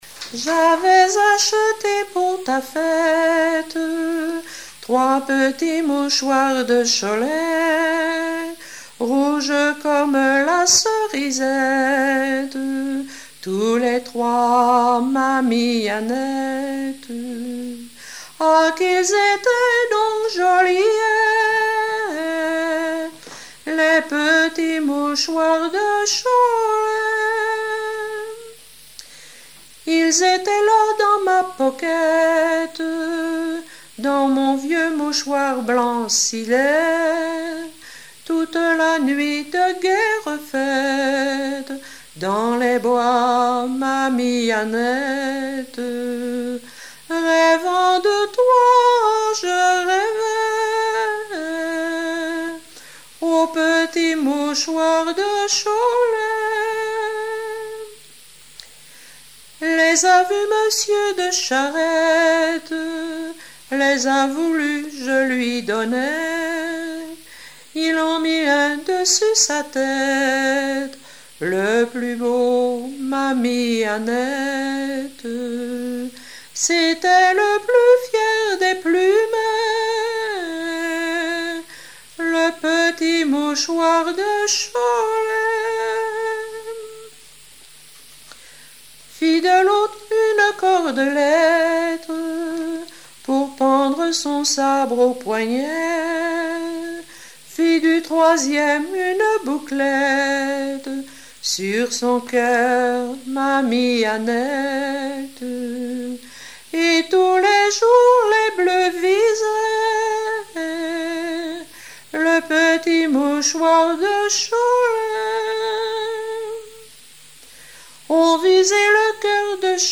Genre strophique
Pièce musicale inédite